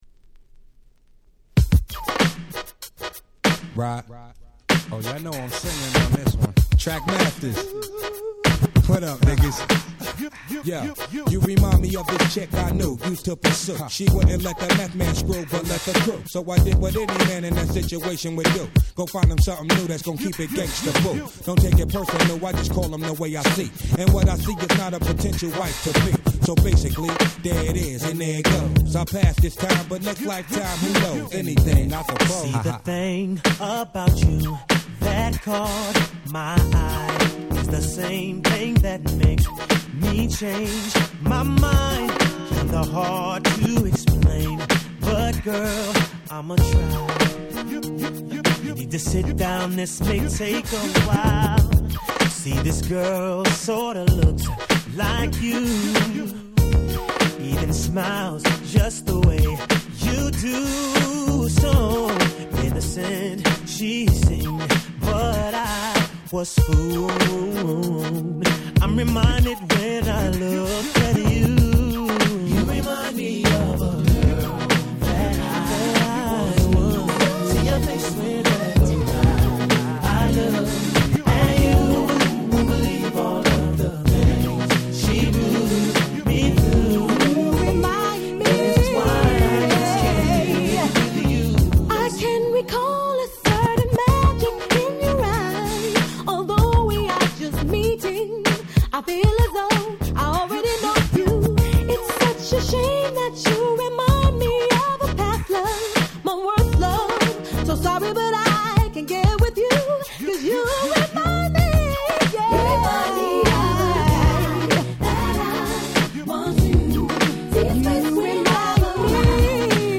01' Super Hit Mid R&B
Smoothでムーディーな最高の1曲。
問答無用の00's R&B Classicsです。